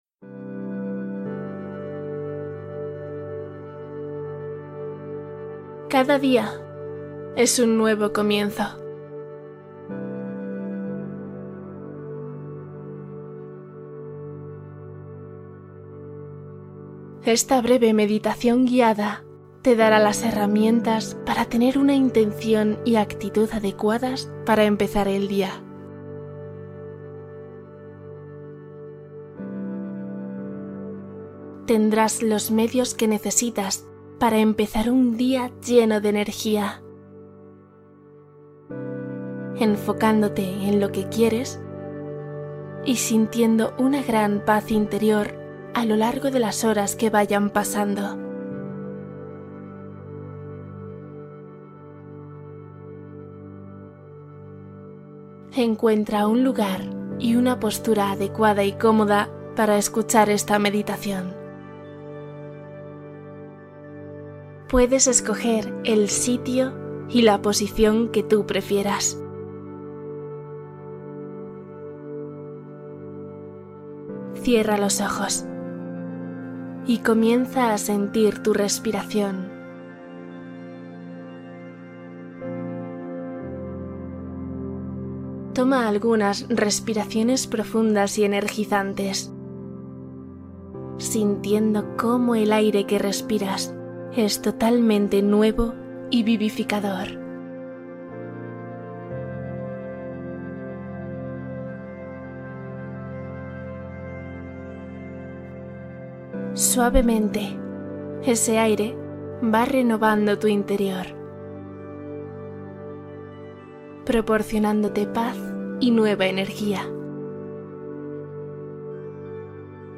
Meditación matinal de 10 minutos para iniciar el día con claridad